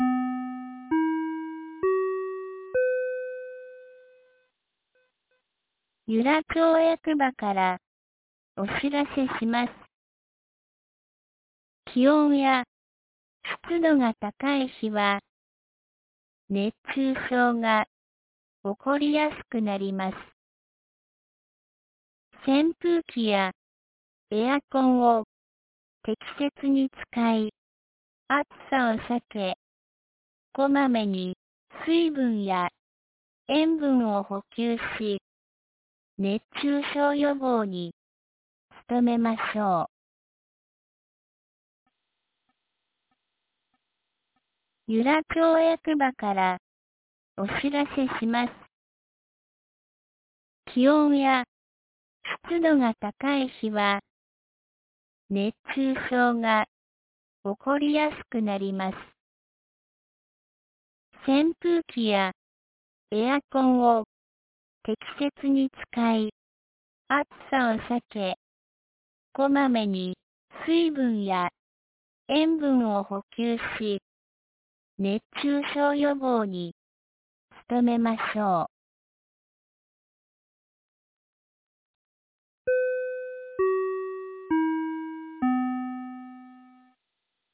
2025年08月27日 07時51分に、由良町から全地区へ放送がありました。